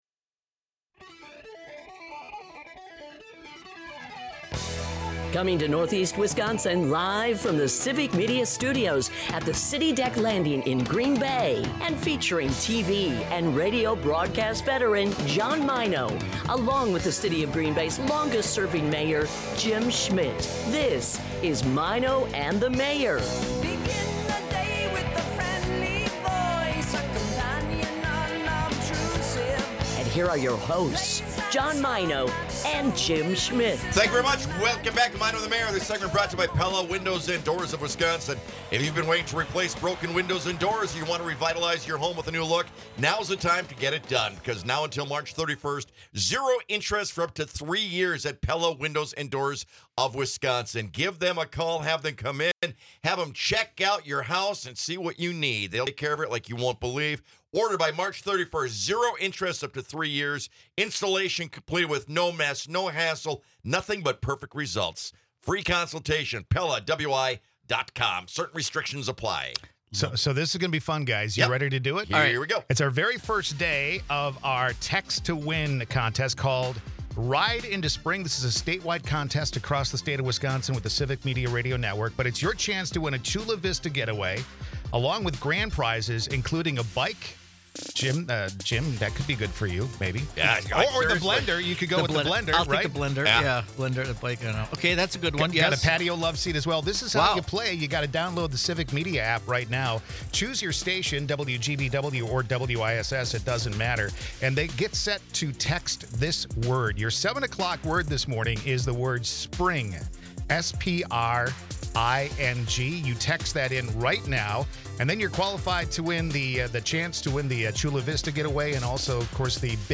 Experience the chemistry and humor between two great friends. Broadcasts live 6 - 9am in Oshkosh, Appleton, Green Bay and surrounding areas.